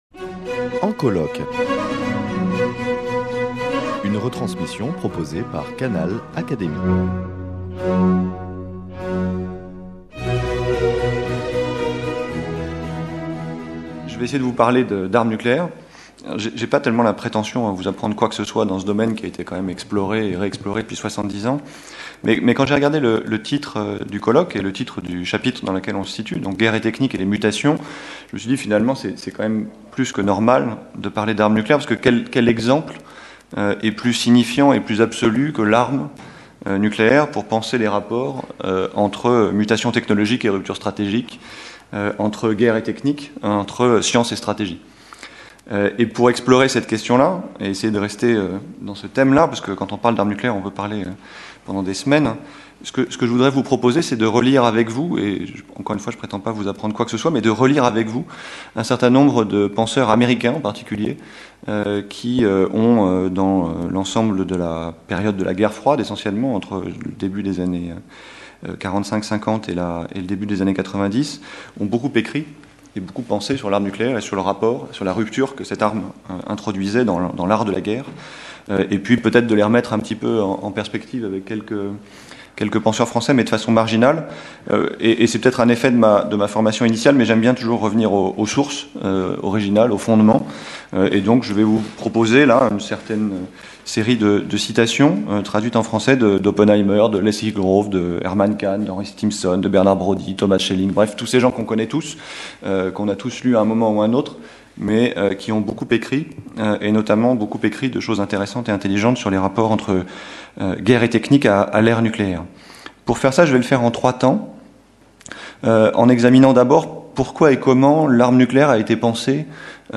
Journées d’étude « Guerre et technique » (4ème partie : Les mutations)